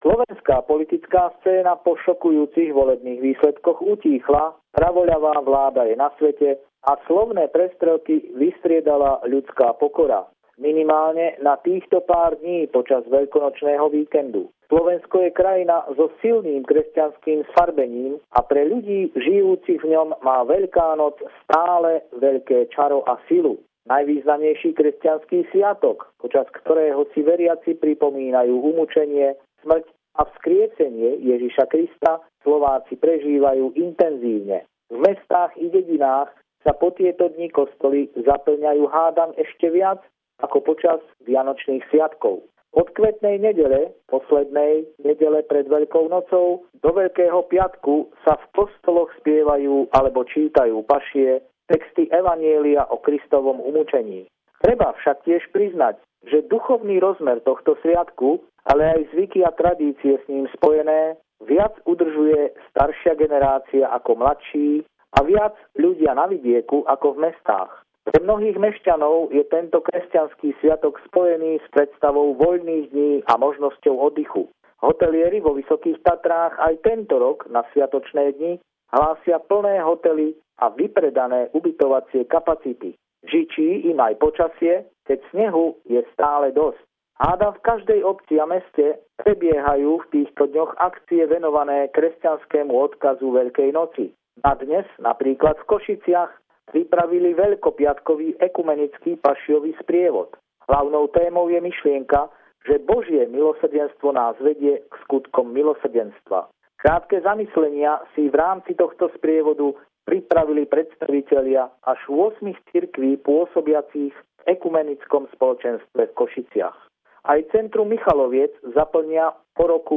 Pravidelný telefonát týždňa z Bratislavy - dnes veľkonočná pohľadnica